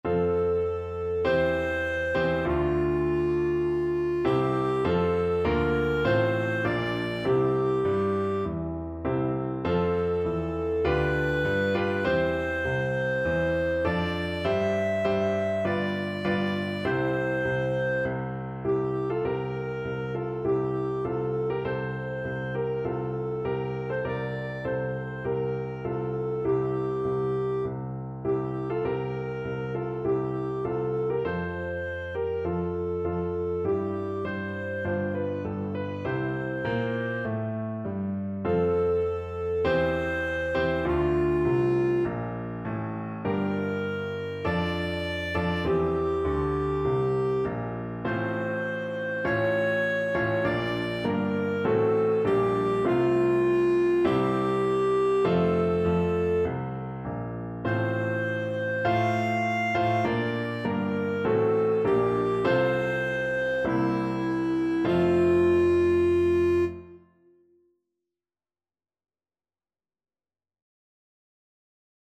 Clarinet
F major (Sounding Pitch) G major (Clarinet in Bb) (View more F major Music for Clarinet )
4/4 (View more 4/4 Music)
Traditional (View more Traditional Clarinet Music)